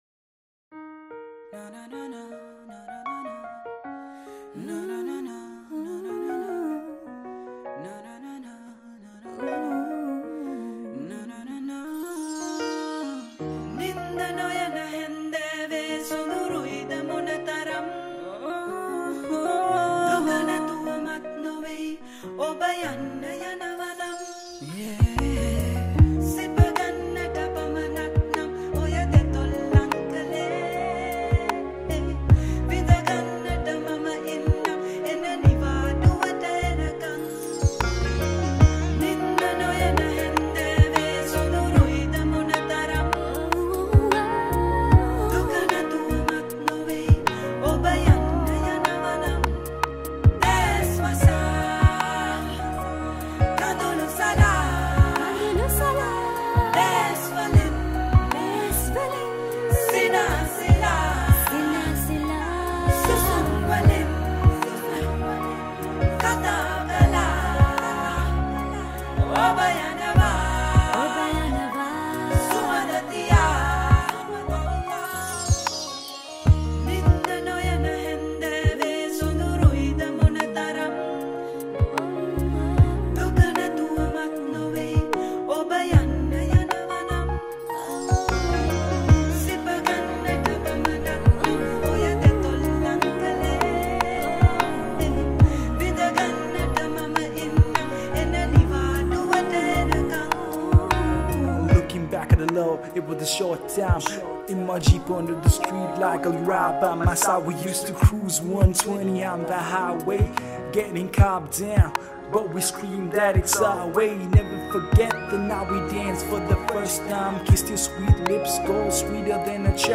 Vocals
sri lankan rnb sri lankan pop